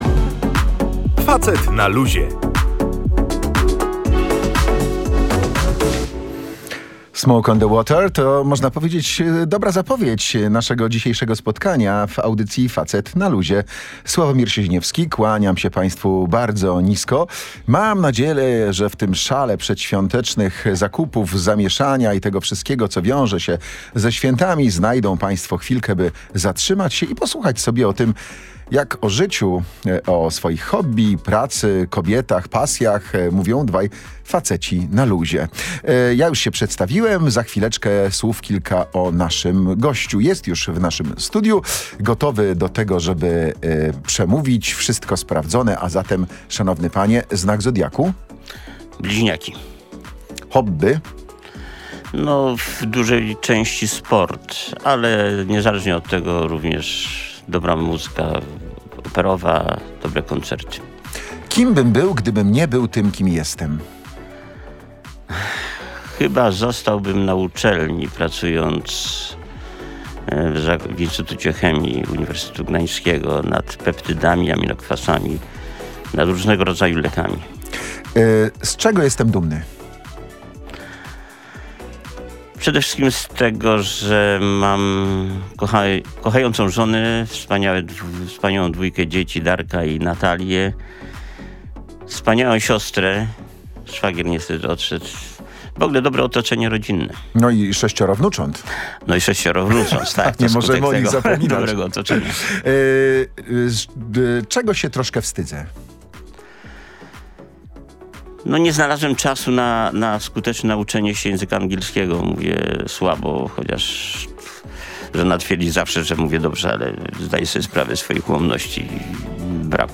O rozwoju firmy opowiadał w audycji „Facet na Luzie” Jan Zarębski.